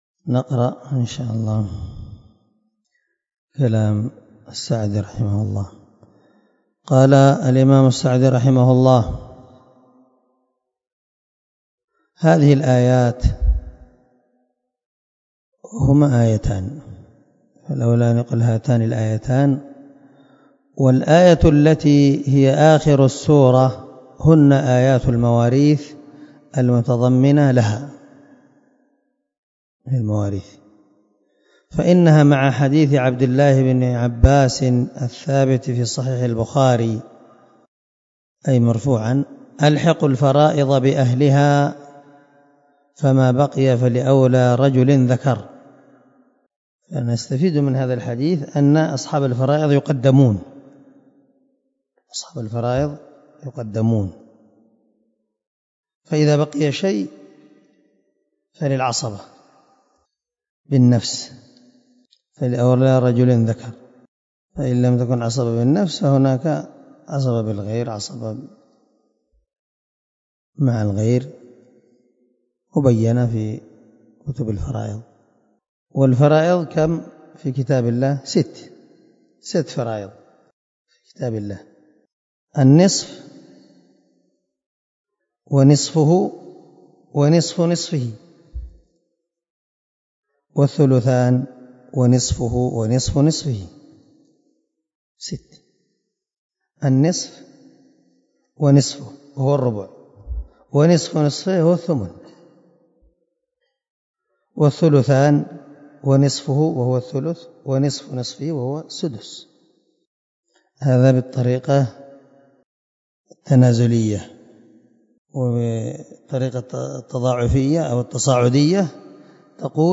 242الدرس 10 تابع تفسير آية ( 11 - 12 ) من سورة النساء من تفسير القران الكريم مع قراءة لتفسير السعدي